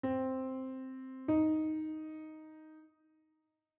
Minor 3rd
C-Minor-Thid-Interval-S1.wav